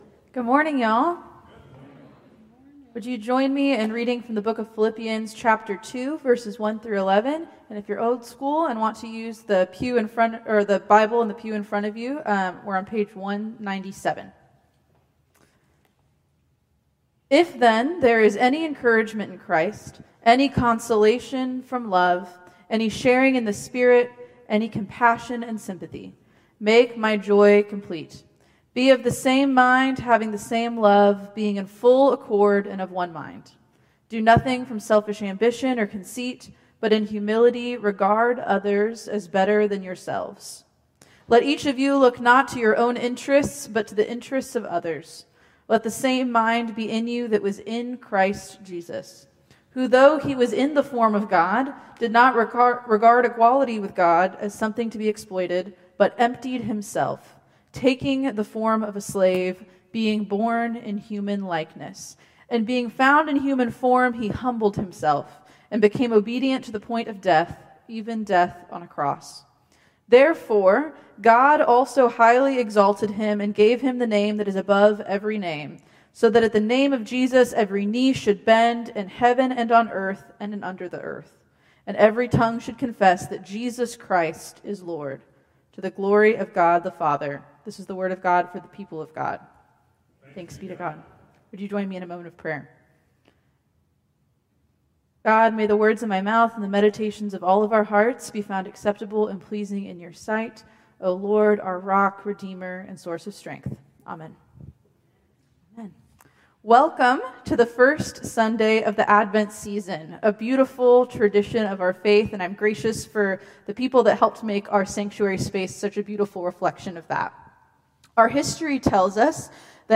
Traditional Service 12/1/2024